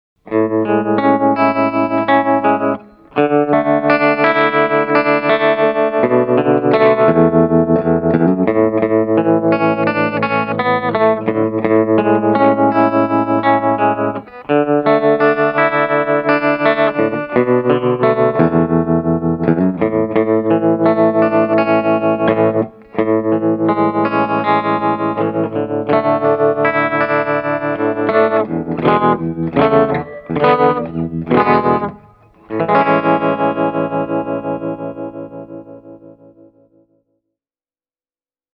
Reverb and echo have been added at the mixing stage.
Studio Custom – ch 2 + tremolo
hamer-studio-ch-2-tremolo.mp3